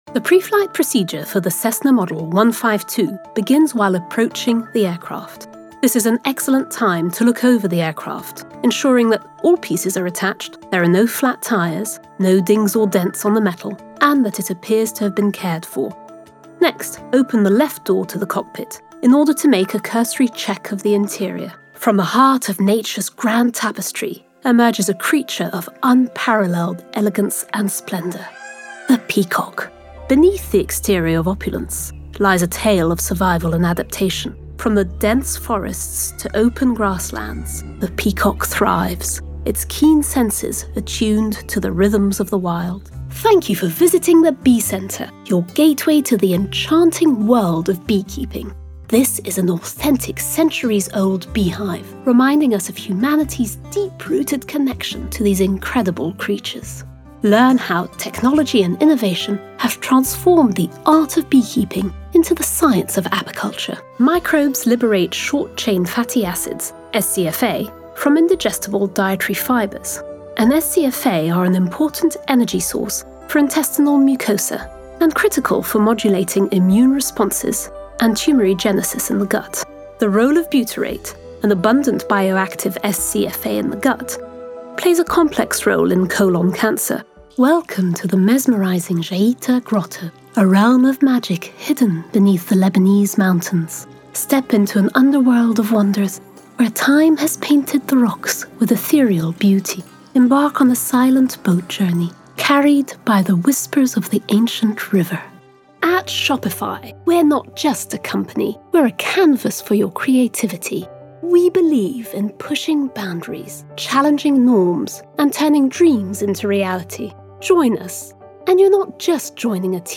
Narration Demo
English - British RP
Young Adult